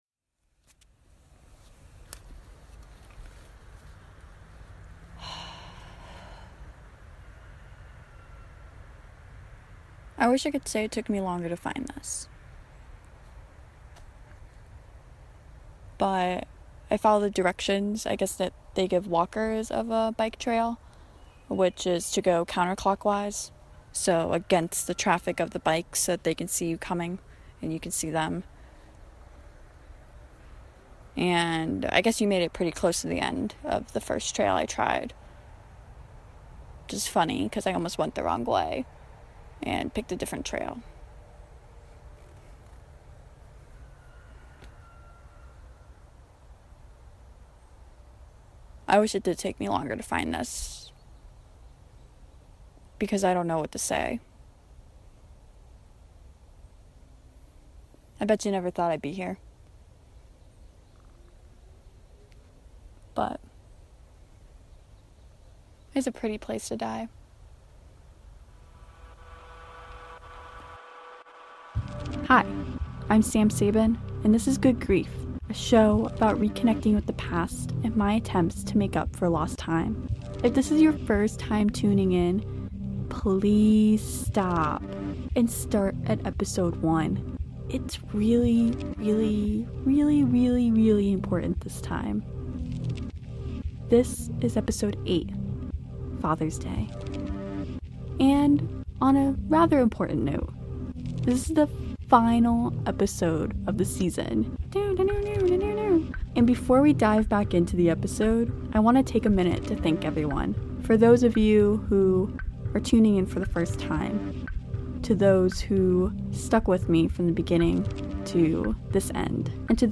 Narration recorded at Sweet Spot Studio.